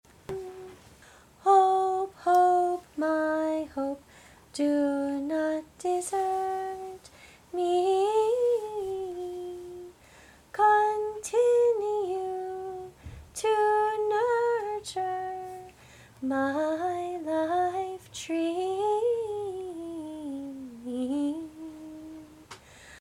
See individual song practice recordings below each score.